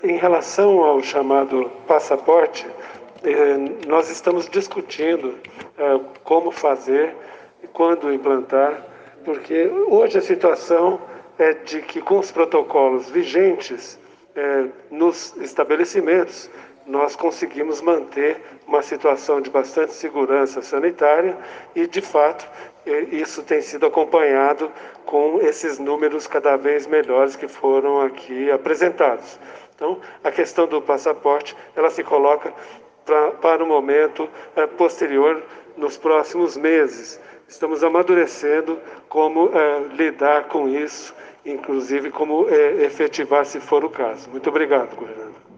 A informação é do coordenador do Centro de Contingência da covid-19 do Estado de São Paulo, Paulo Menezes, em entrevista coletiva, ao lado do governador no início da tarde desta quarta-feira, 04 de agosto de 2021.